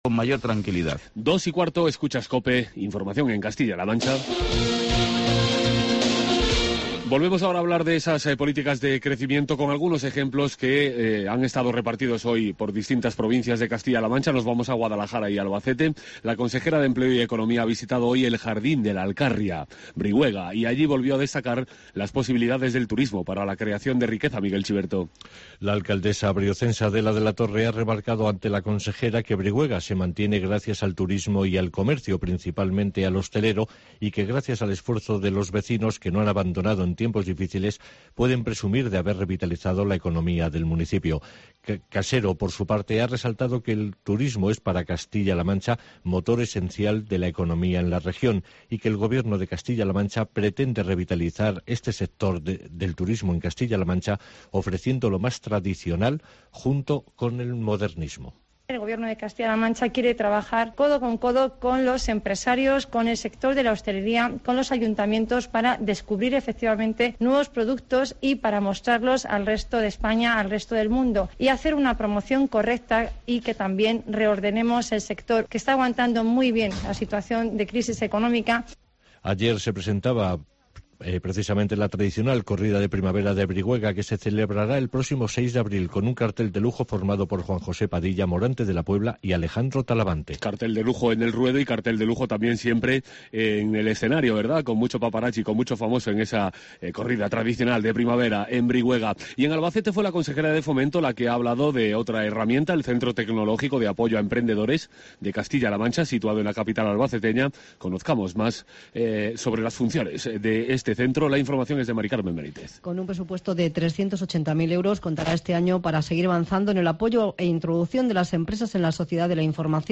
Informativo Guadalajara 22 de febrero